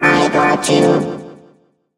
evil_rick_kill_vo_03.ogg